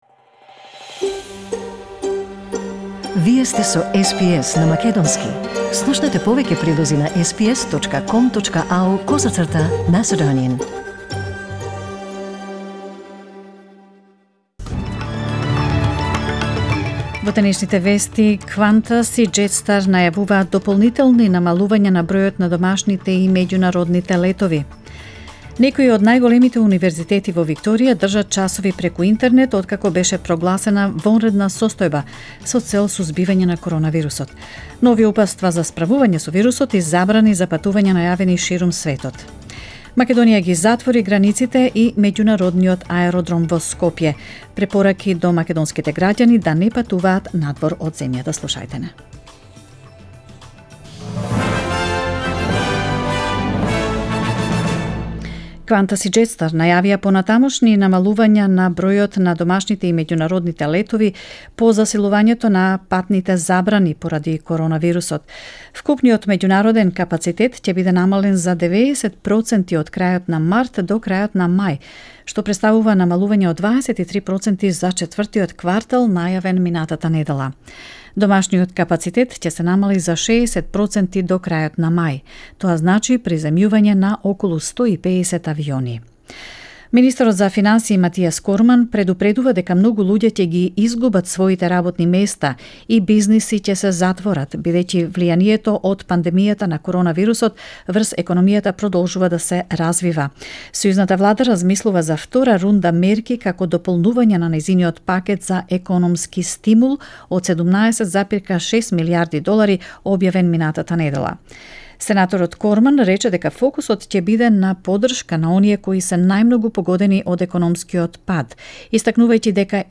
SBS News in Macedonian 17 March 2020